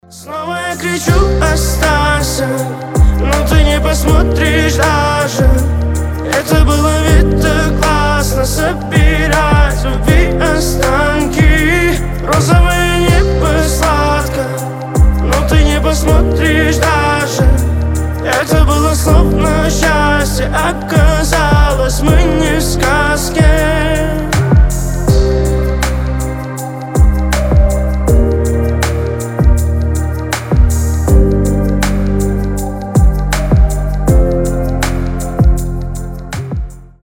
• Качество: 320, Stereo
красивые
лирика
спокойные